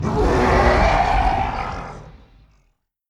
mgroan11.mp3